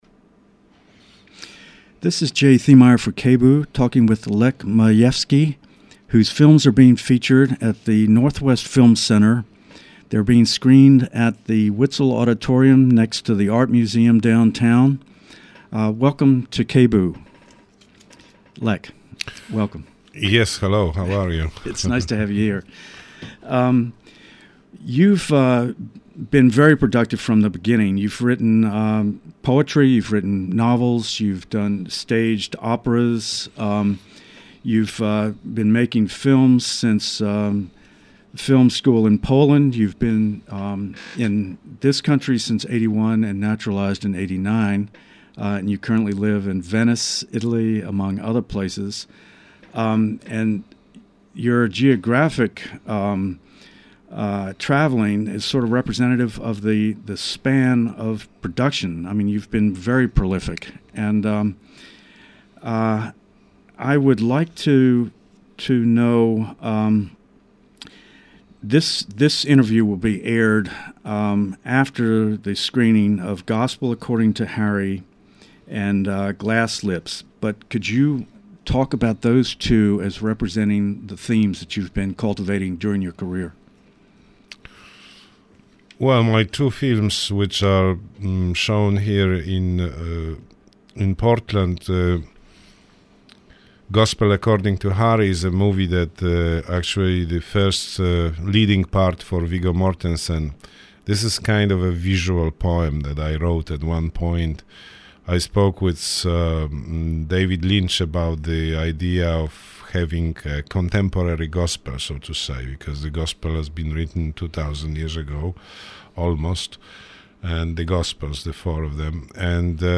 Lech Majewski Interview